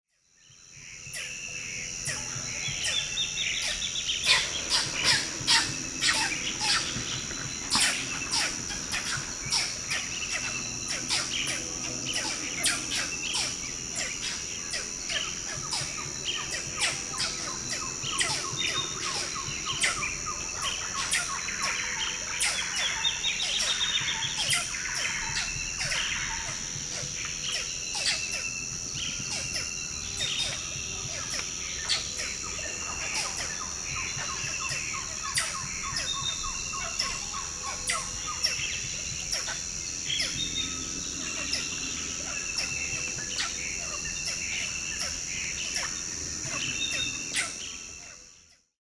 When they sense a threat nearby, Squirrel Monkeys make dry, sneezing alarm calls.
Humboldt’s Squirrel Monkey – Alarm calls
XC979834 This sample features alarm calls of Humboldt’s Squirrel Monkeys (Saimiri cassiquiarensis). Recorded in Cuyabeno Wildlife Reserve, Ecuador.